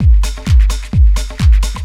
NRG 4 On The Floor 033.wav